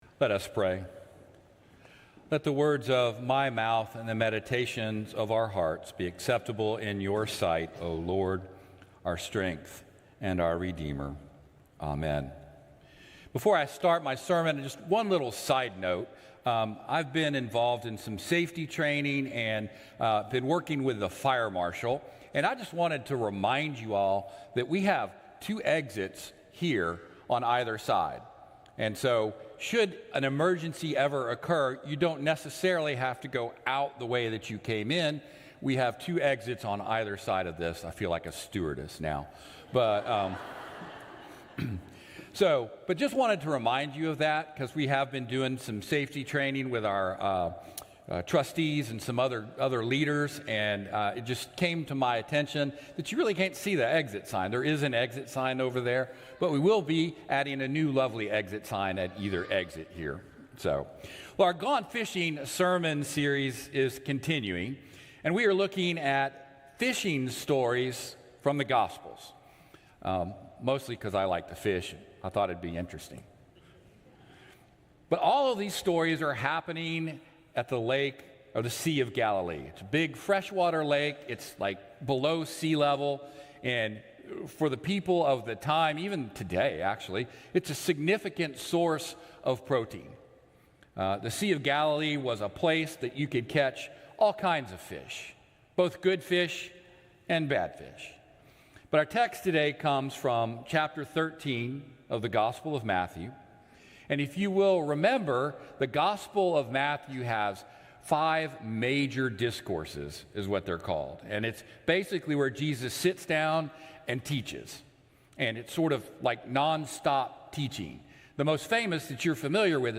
Traditional-Service-—-Jul.-16.mp3